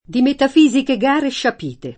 scipito